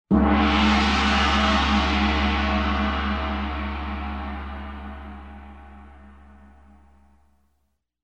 Gong ringtone download